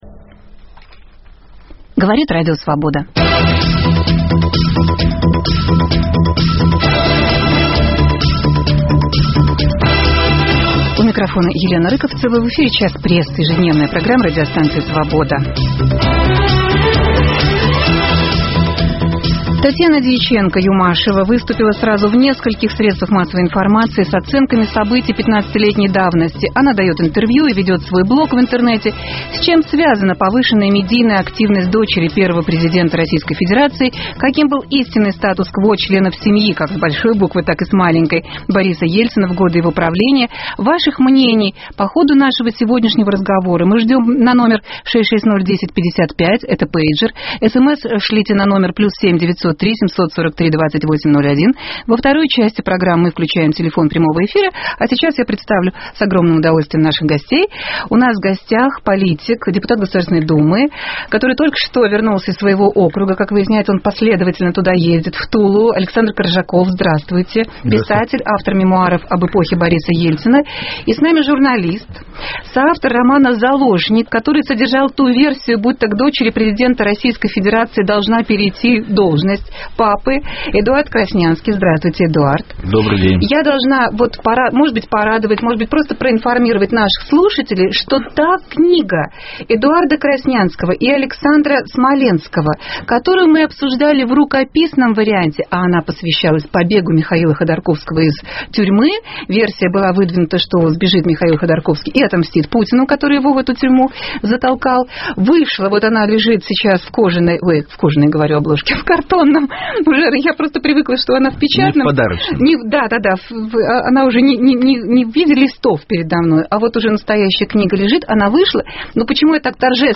Обсуждают политик и автор мемуаров о Борисе Ельцине Александр Коржаков и журналист